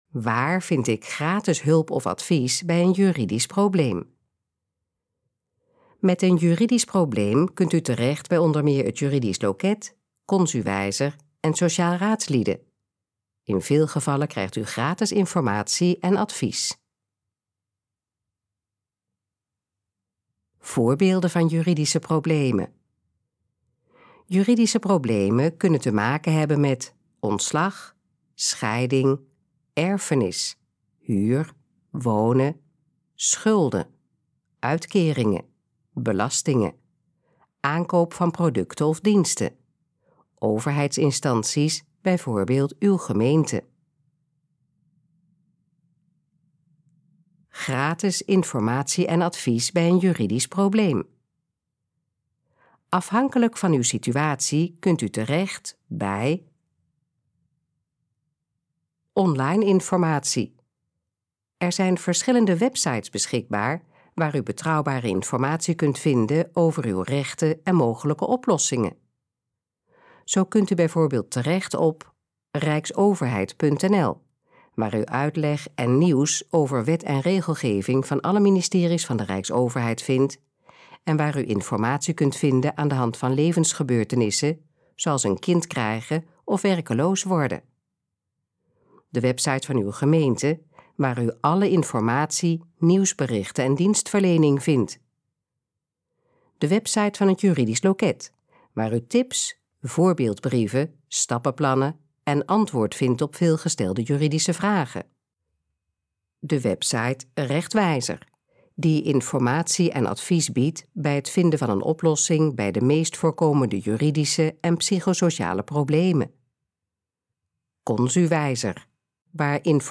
Gesproken versie van: Waar vind ik gratis hulp of advies bij een juridisch probleem?
Dit geluidsfragment is de gesproken versie van de pagina: Waar vind ik gratis hulp of advies bij een juridisch probleem?